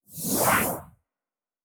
Synth Whoosh 1_2.wav